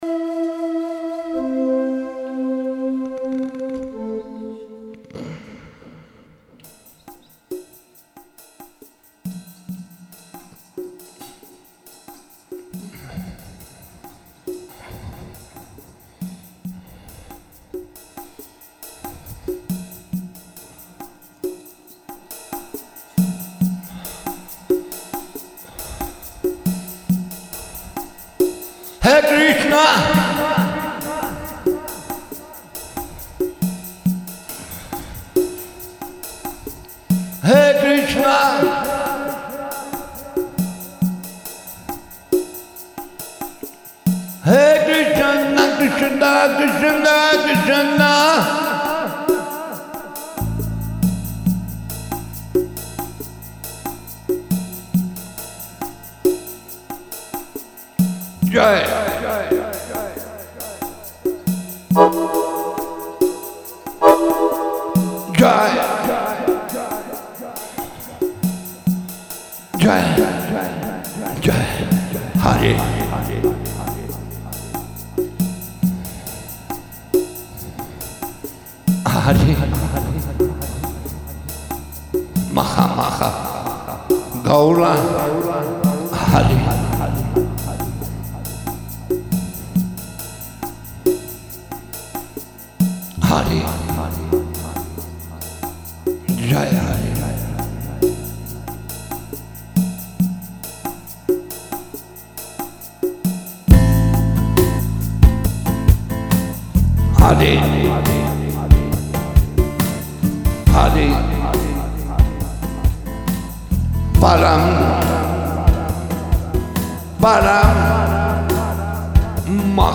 Бхаджаны